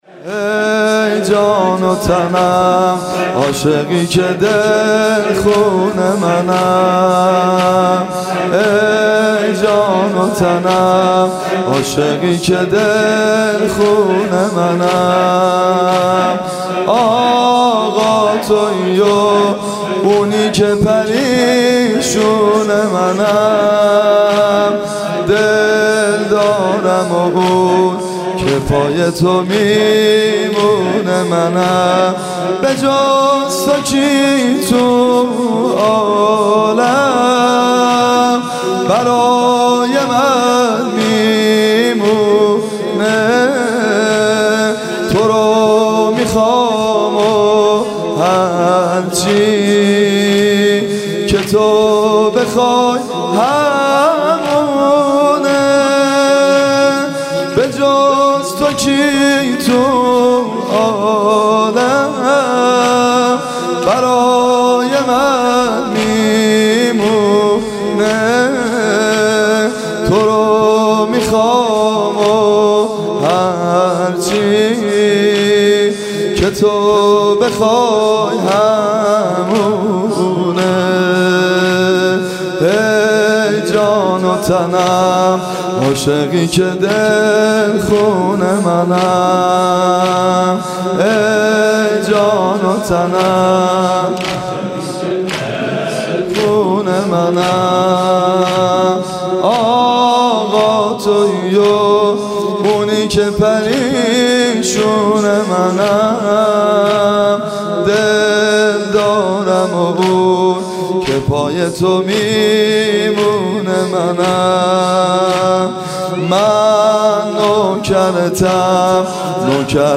شب هشتم و شب تاسوعای حسینی
مداحی